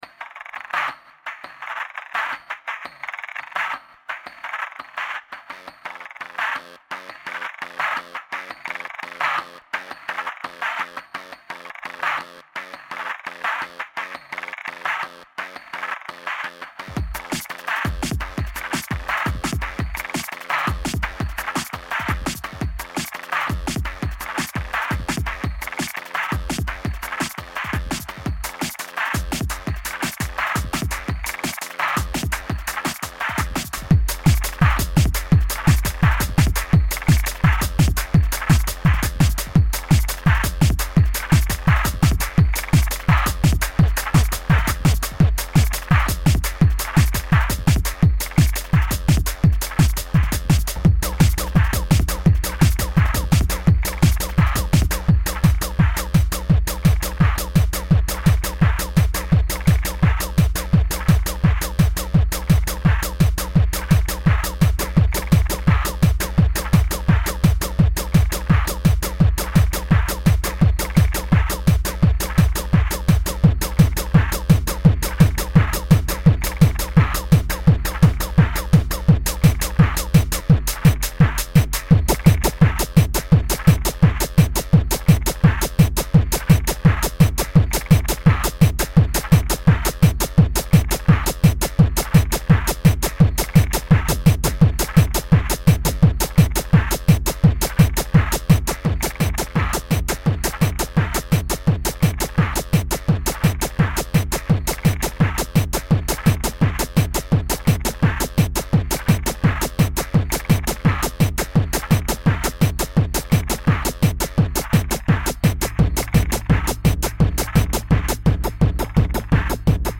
TEKNO Descàrregues i reproduccions